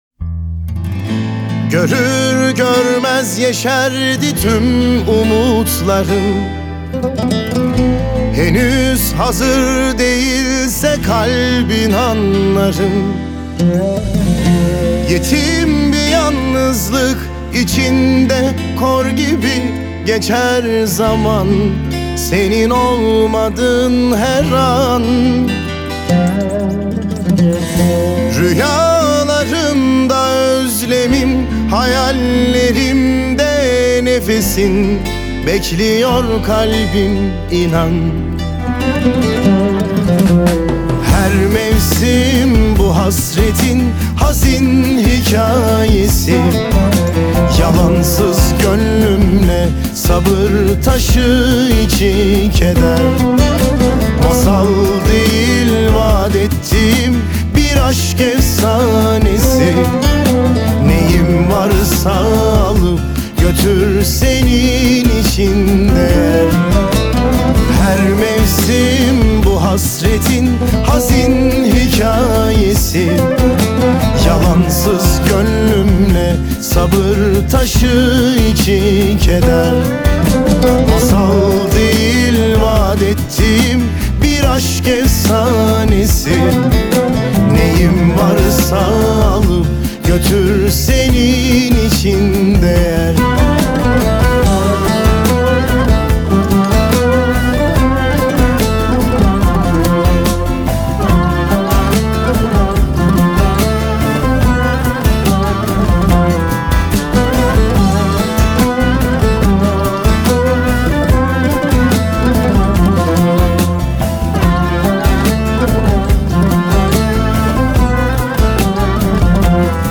دانلود آهنگ غمگین ترکی استانبولی – ۱